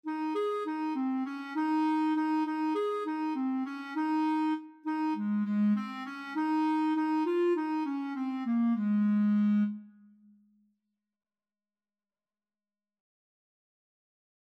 World Trad. Pimpon (SpanishTraditional) Clarinet version
Ab major (Sounding Pitch) Bb major (Clarinet in Bb) (View more Ab major Music for Clarinet )
2/4 (View more 2/4 Music)
Quick
Ab4-Ab5
World (View more World Clarinet Music)
pinpon_CL.mp3